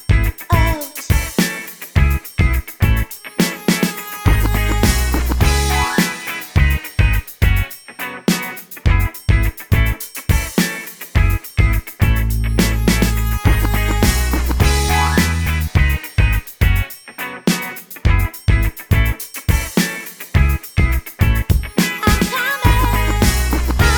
for duet R'n'B